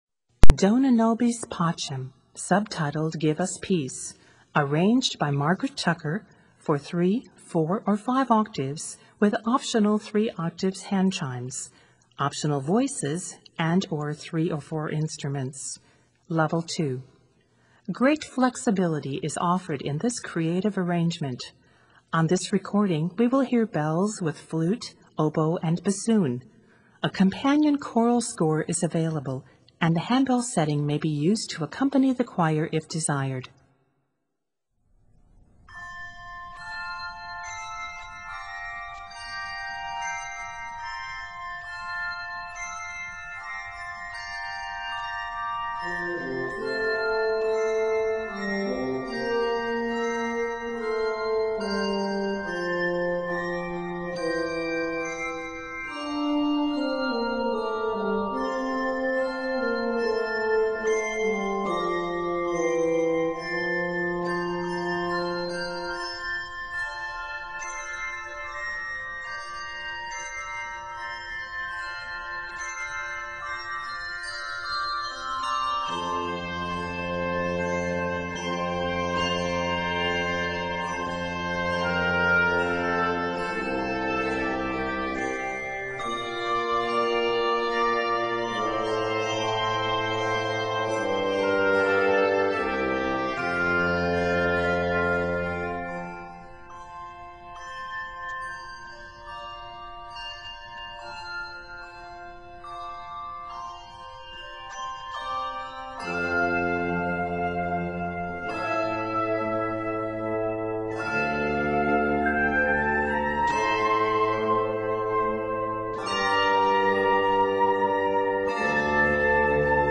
traditional Latin canon
handbells